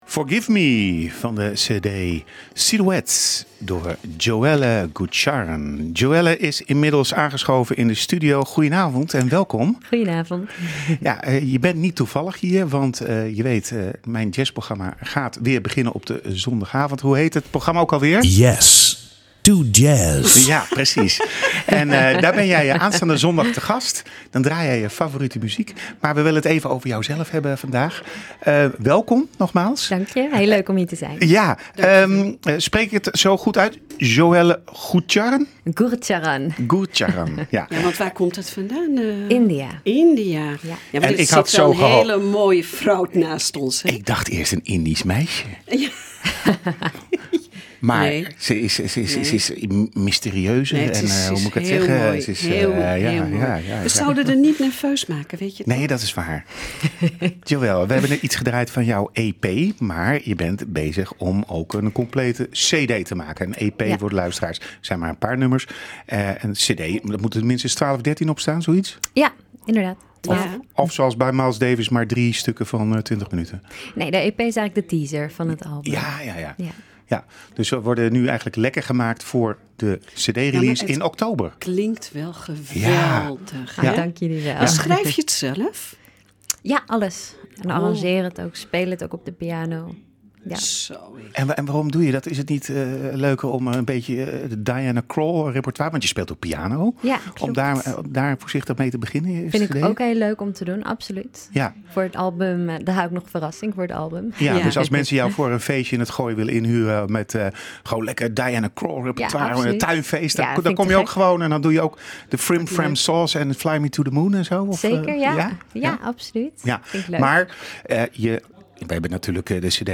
Maar het interview staat nu wel vast online